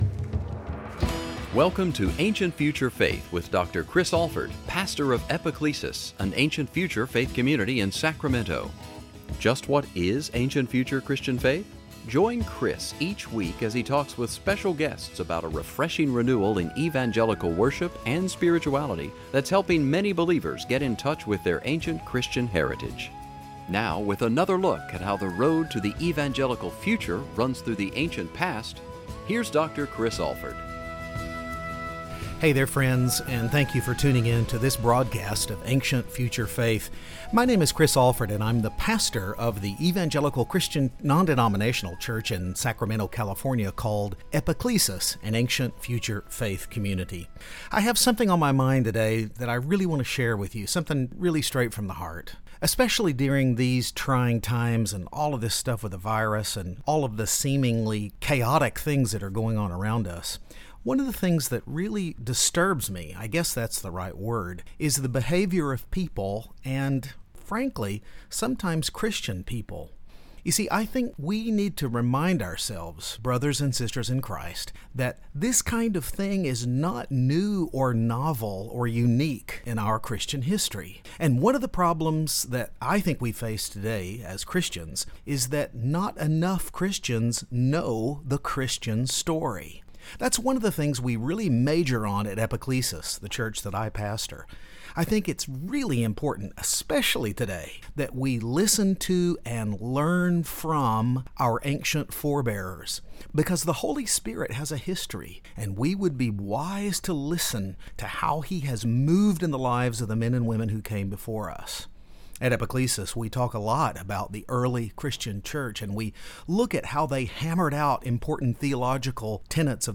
sermon
for a radio broadcast on station KFIA (710 AM, 105.7 PM)